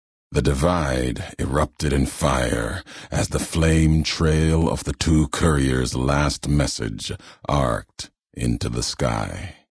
Categoty:Lonesome Road endgame narrations Du kannst diese Datei nicht überschreiben.